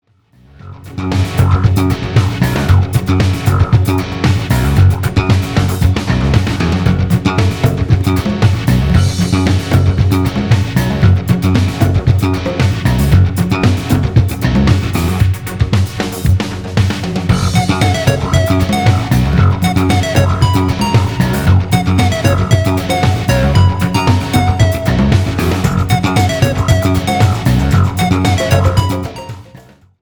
FUNK  (2.57)